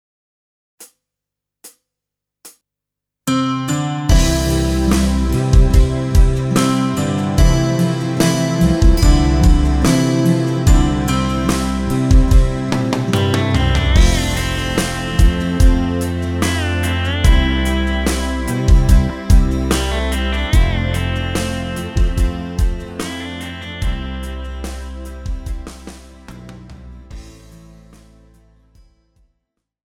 Žánr: Pop
BPM: 72
Key: C